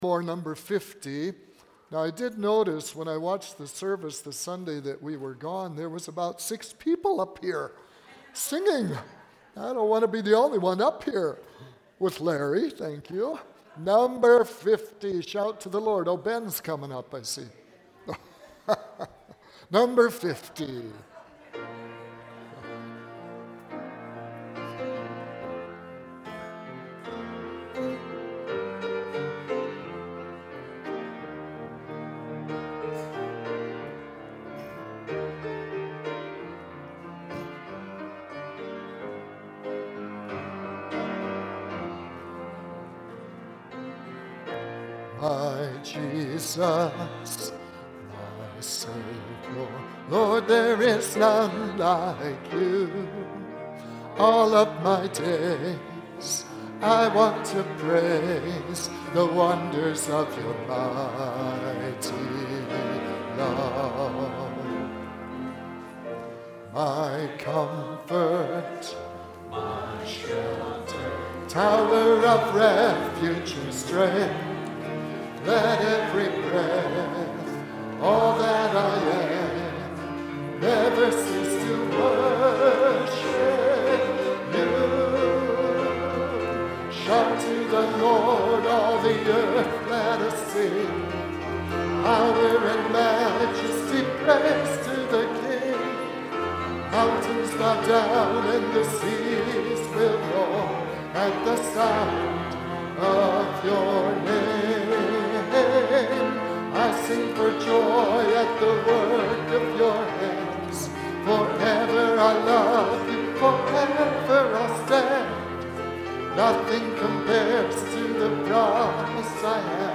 Bible Text: I Corinthians 15:50-58 | Preacher